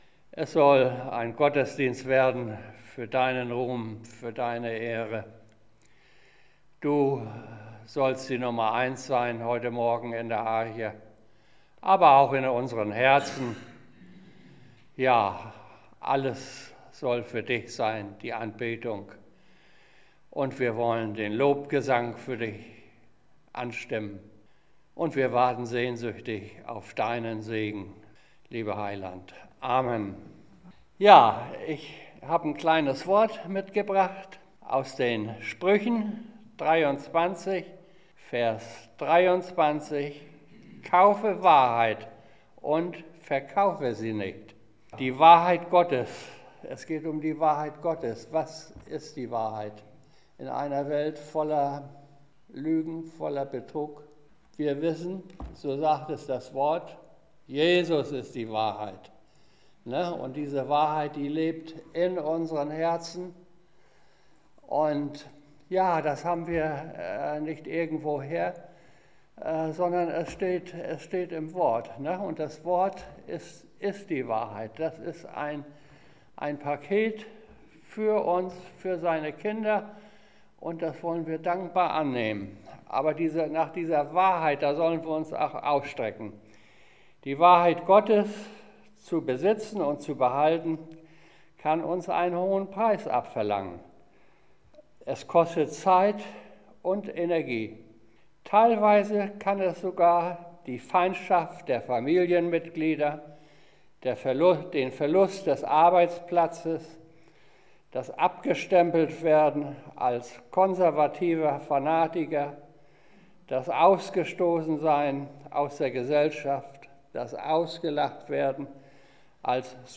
Predigt und Zeugnisse